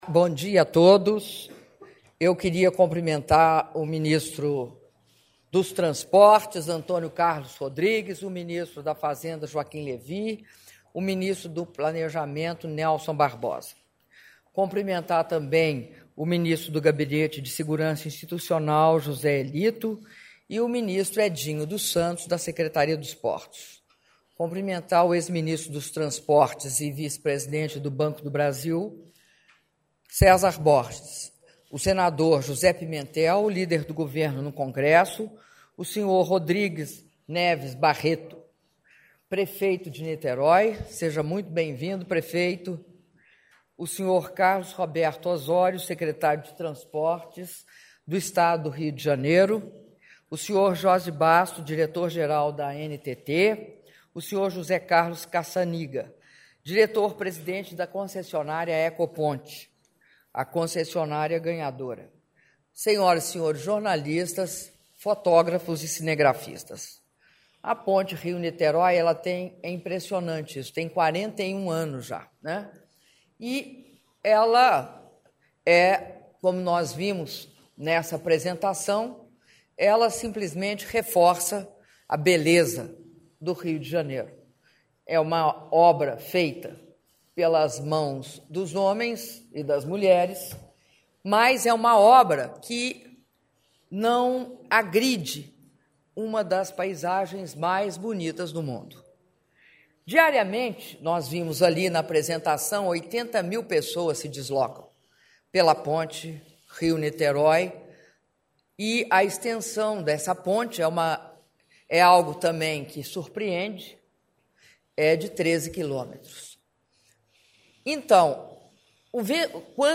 Áudio do discurso da presidenta da República, Dilma Rousseff, na cerimônia de assinatura do contrato de concessão da Rodovia BR-101/RJ - Ponte Rio-Niterói - Brasília/DF (7min32s)